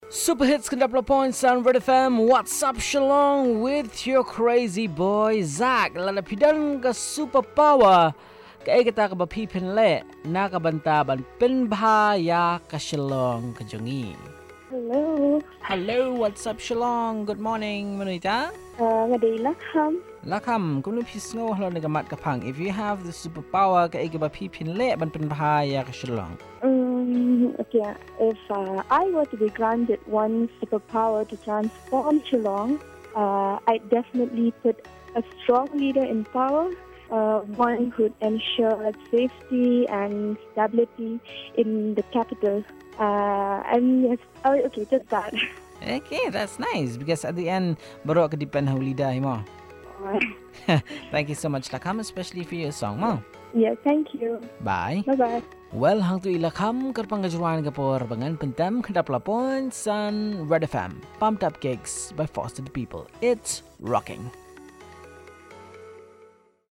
Caller 2 on having a Superpower to make Shillong better